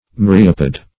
Myriapod \Myr"i*a*pod\, n. [Cf. F. myriapode.] (Zool.)